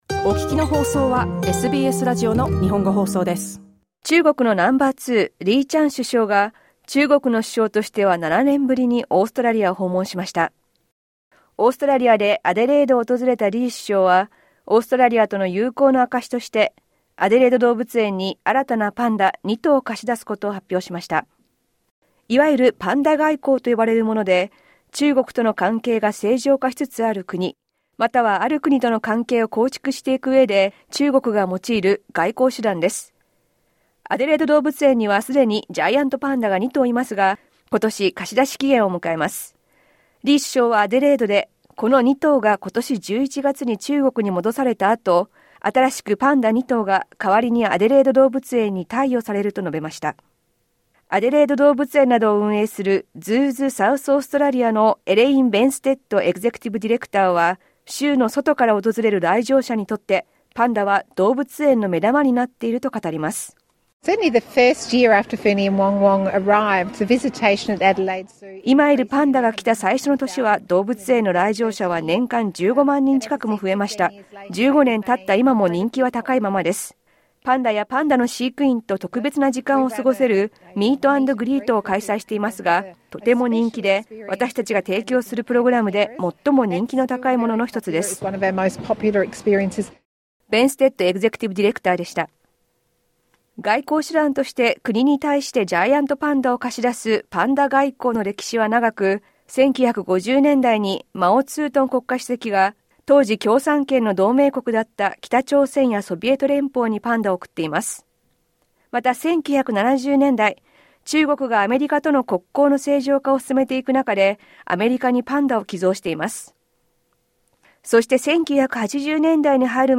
詳しくは音声リポートからどうぞ。